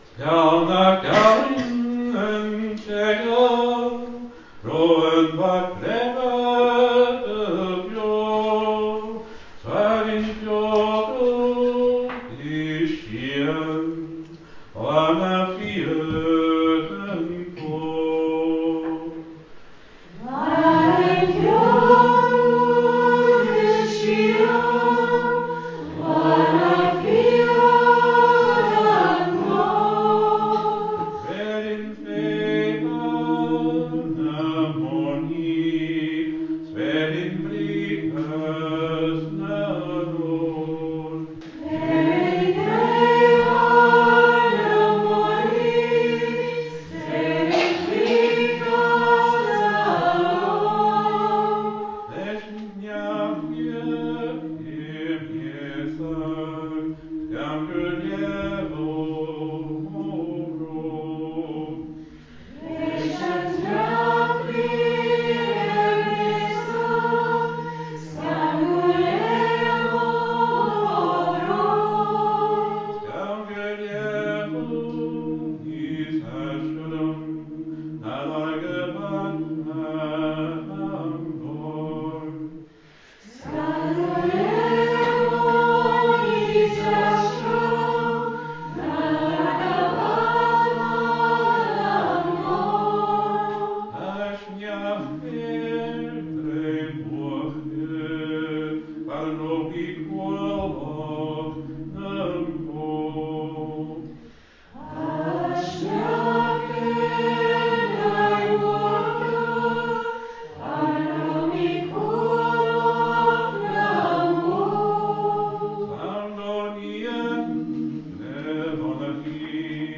Here are recordings from the cèilidh on Thursday night when we sang a some of the songs we learnt:
Tha Sneachd air Druim Uachdair (There is Snow on Druim Uachdair) – a song from the 17th century by Fearchair Mac Iain Oig from Kintail about a man who is on the run from the law and is hiding out in the hills where it’s cold and lonely. I sang solo for parts of this.